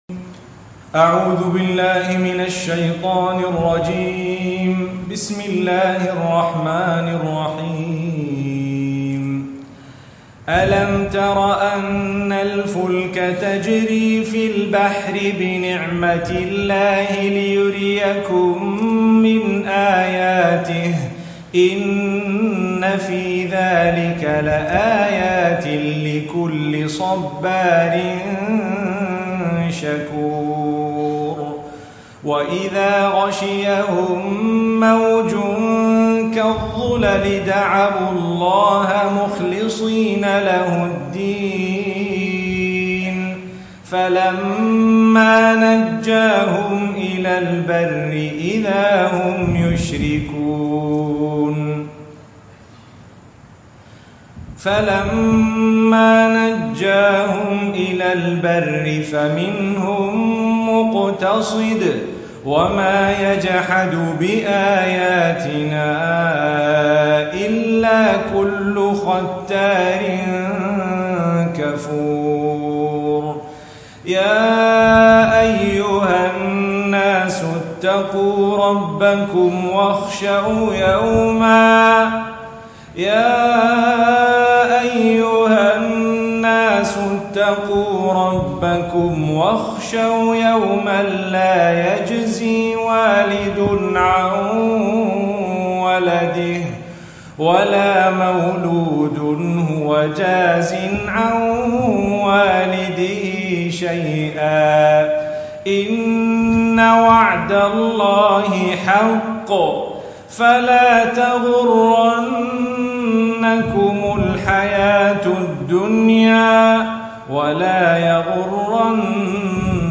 Lecture at the At-Taqwa Mosque, Taman Tun Dr ismail, Kuala Lumpur, Malaysia on 15th Dec 2012.